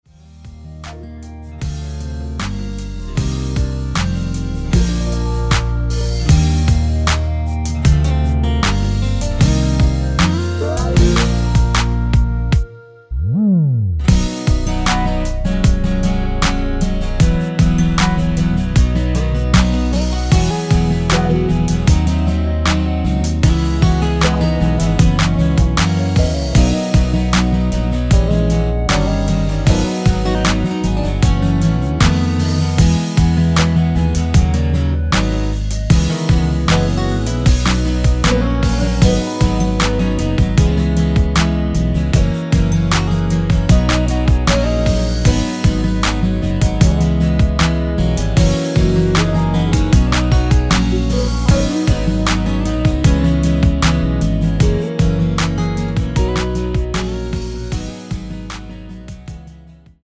원키에서(-5)내린 멜로디 포함된 MR입니다.(미리듣기 확인)
Bb
앞부분30초, 뒷부분30초씩 편집해서 올려 드리고 있습니다.